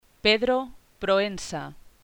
Pedro Proença Oliveira Alves GarciaPédro Proénsa